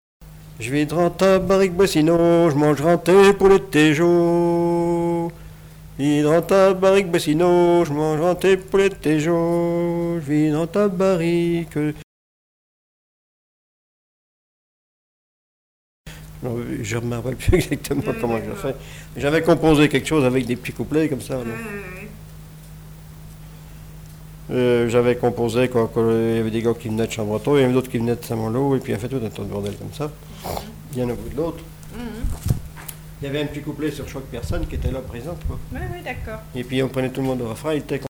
bachique
Genre brève
Témoignages et chansons
Pièce musicale inédite